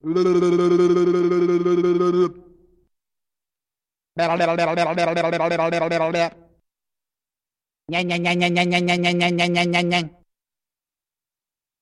Звуки высовывания языка
Звук дразнящего удара пальцем по высунутому языку